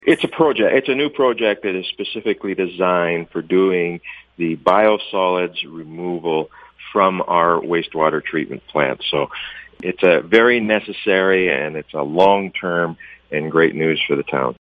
Mayor Ron Toyota says the town currently has short-term solutions but this project will save on costs.